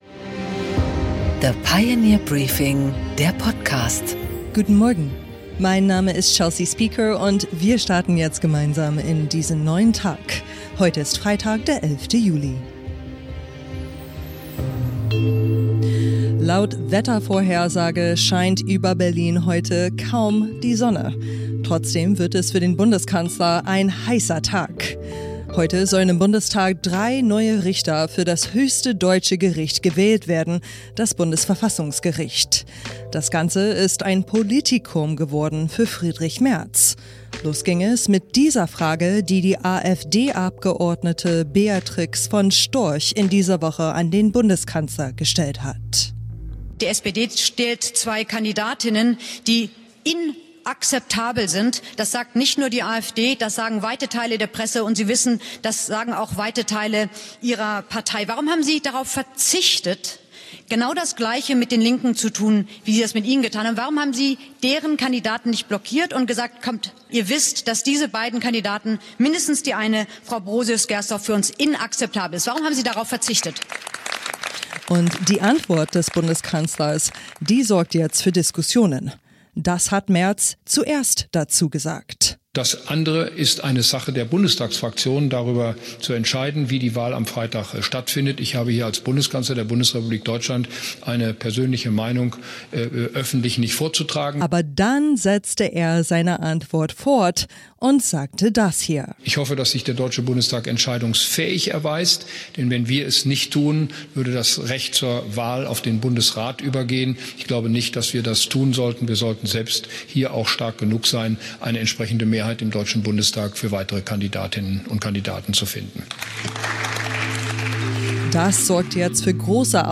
Im Interview: Wolfram Weimer, ehemaliger Chefredakteur und Cicero-Gründer, spricht mit Gabor Steingart über seine neue Rolle als Staatsminister für Kultur und Medien, über Werte, Konservatismus und warum die AfD keine konservative Partei ist.